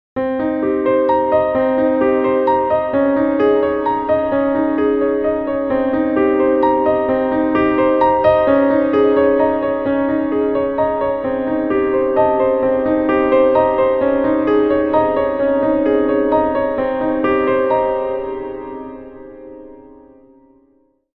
Genres: Sound Logo Artist